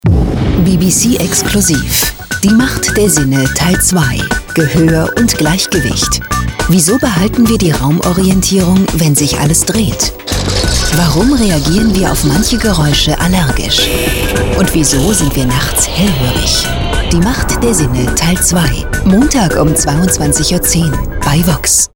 Vielseitige Profi-Sprecherin deutsch: Werbung, TV-Trailer und voice over für VOX, Phoenix.
Sprechprobe: Sonstiges (Muttersprache):
german female voice over artist.